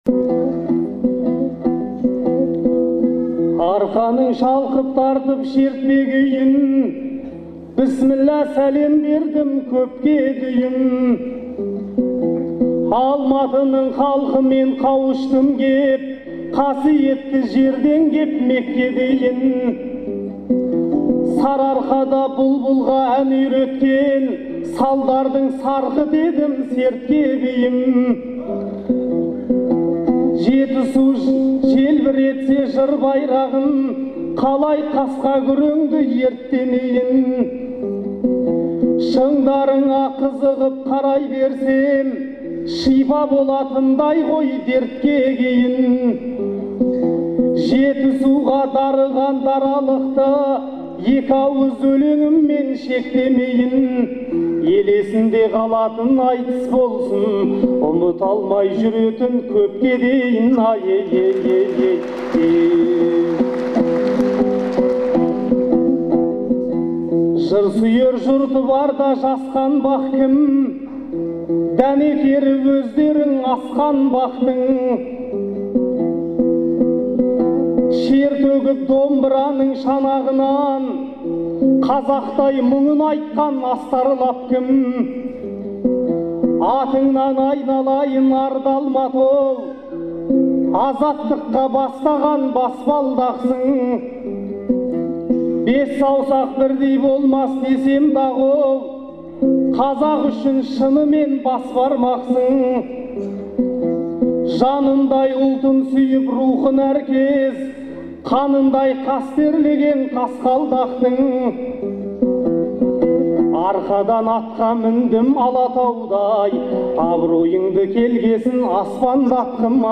Алматыда өткен «Төртеу түгел болса» атты айтыста